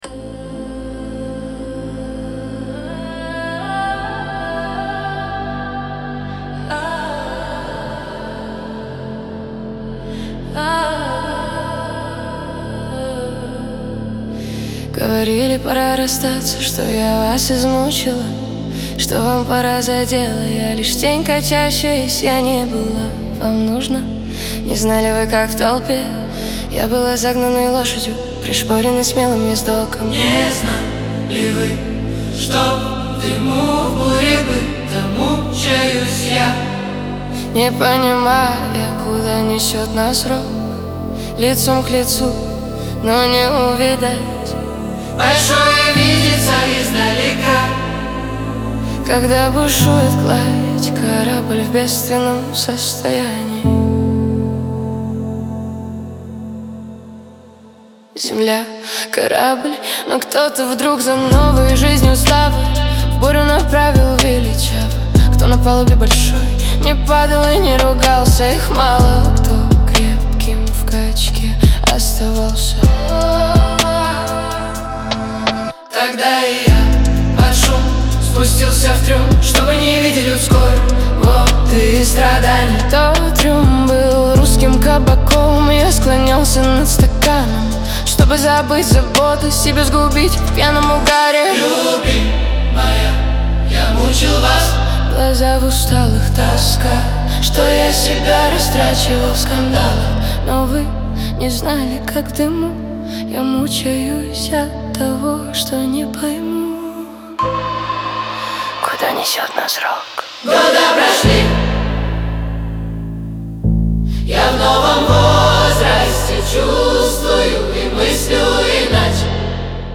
Трек размещён в разделе Рэп и хип-хоп / Русские песни / Поп.